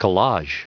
Prononciation du mot collage en anglais (fichier audio)
Prononciation du mot : collage